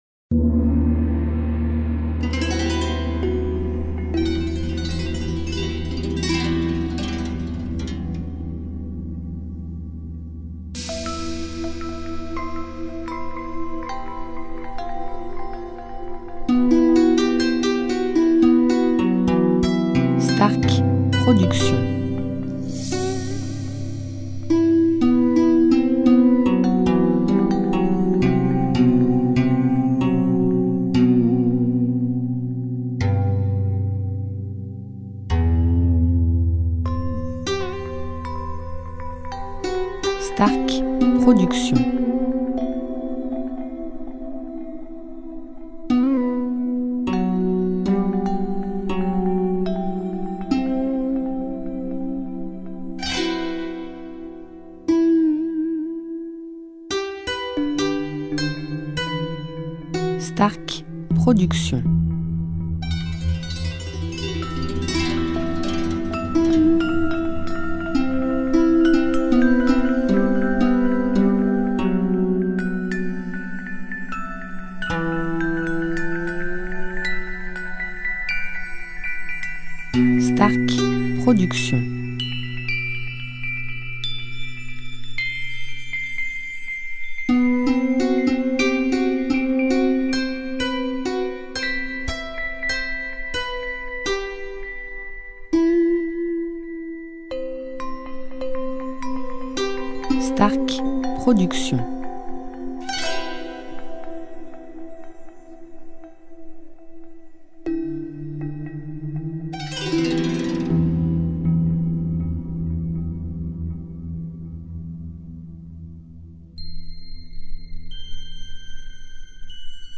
style Asie